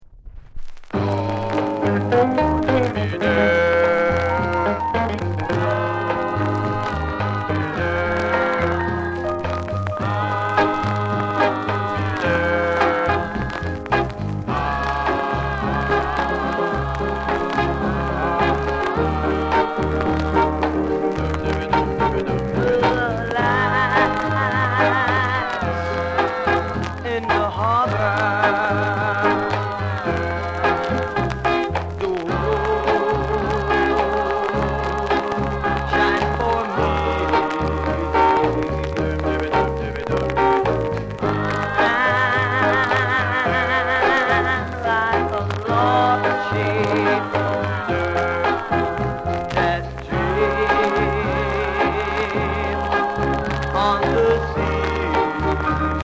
PLAY OK ������ RARE SKA